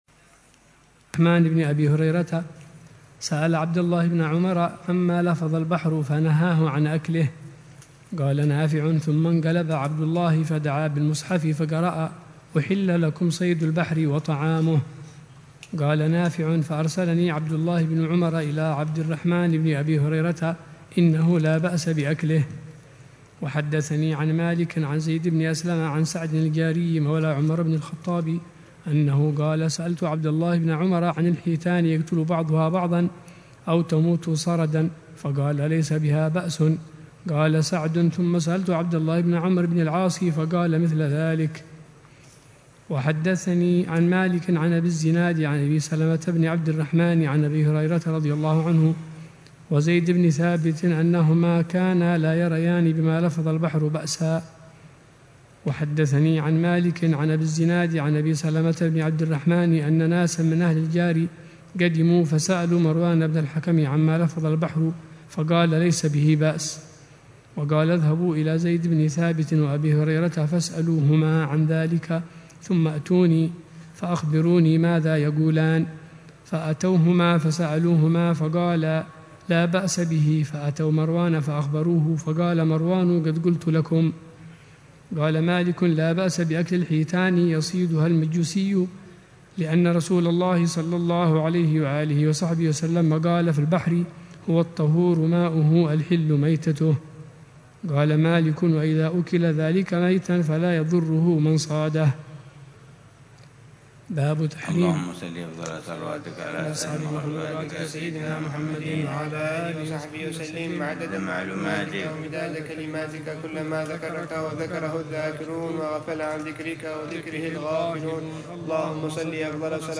شرح